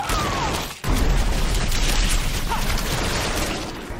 Download Rain of Arrow sound effect for free.